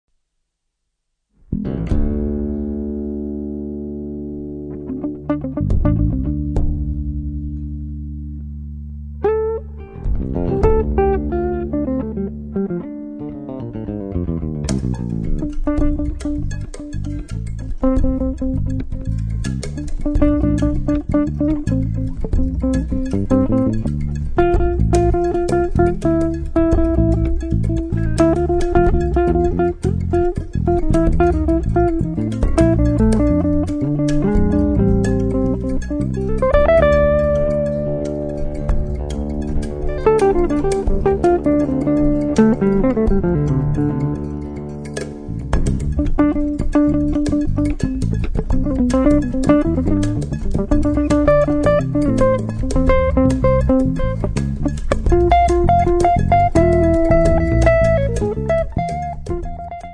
chitarre